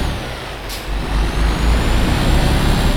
Index of /server/sound/vehicles/lwcars/truck_2014actros